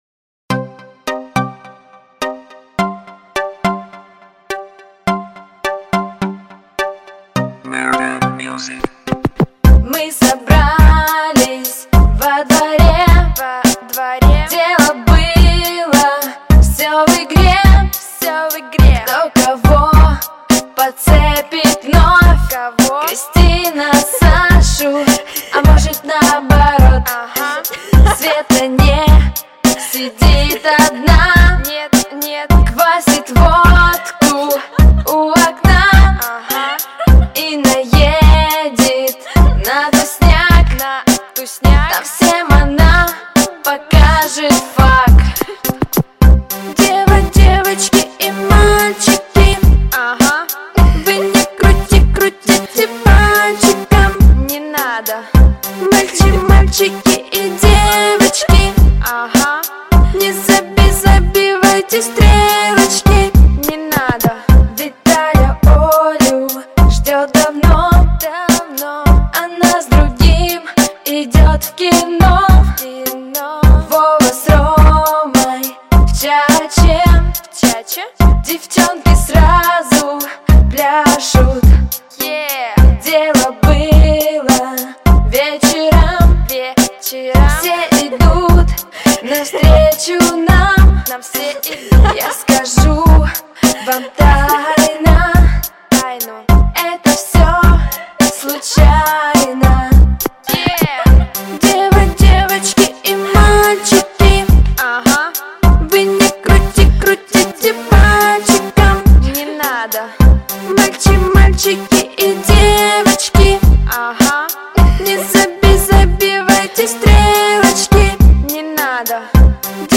[10/9/2008]俄罗斯节奏慢摇:"你哪德?" o(∩_∩)o 激动社区，陪你一起慢慢变老！